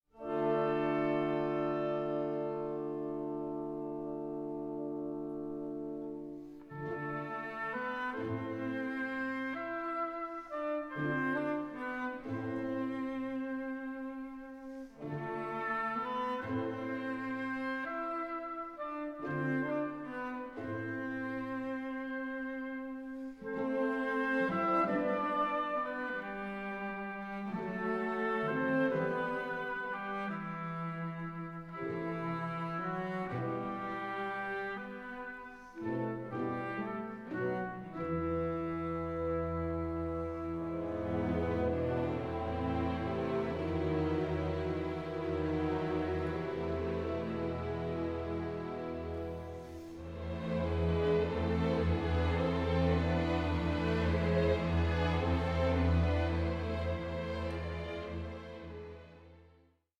Romanze 4:07